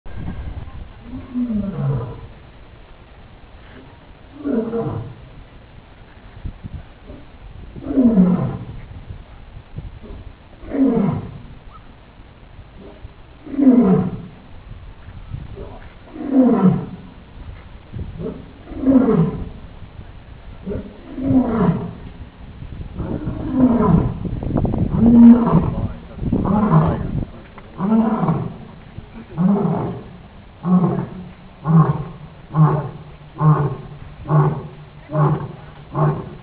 Zoolion.wav